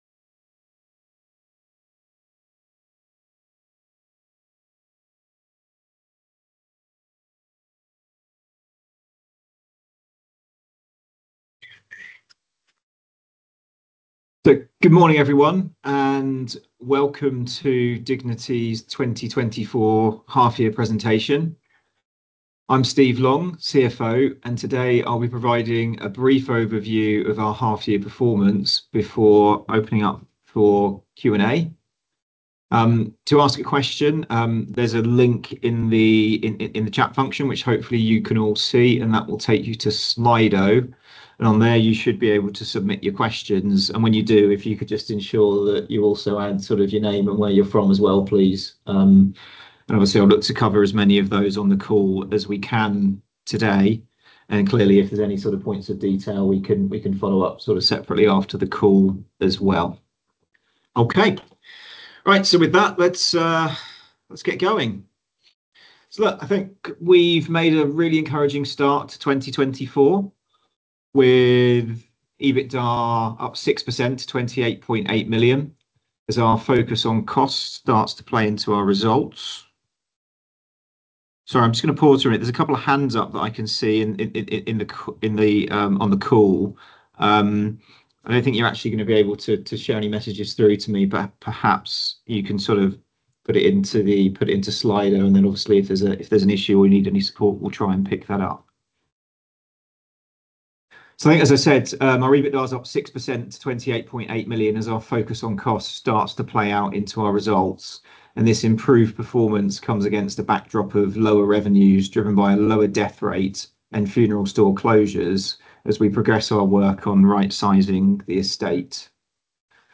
Call recording of the Dignity Bondholder Presentation 52 Weeks Ended 28 June 2024 26 September 2024 Download Call recording of the Dignity Bondholder Presentation 52 Weeks Ended 28 June 2024 PDF (10.91 Mb)